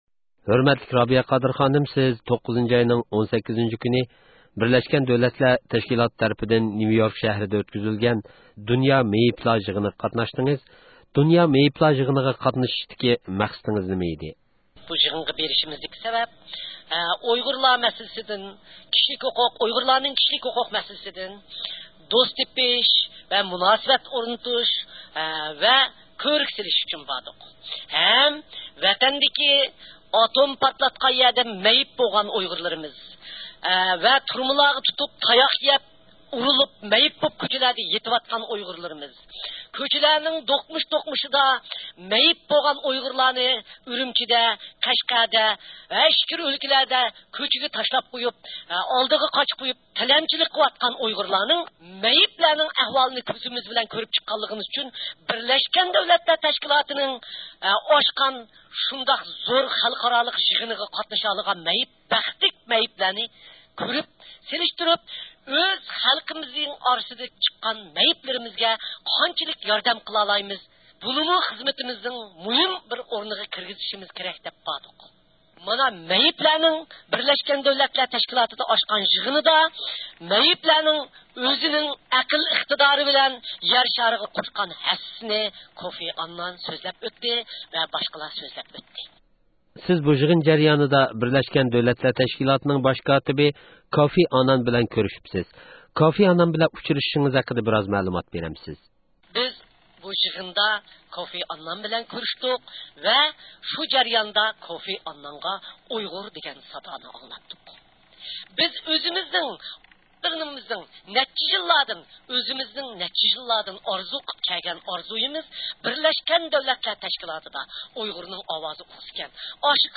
رابىيە خانىمنى زىيارەت قىلىپ، نيۇ-يوركتىكى ئۇچرىشىشلىرى ھەققىدە سۆھبەتلەشتى.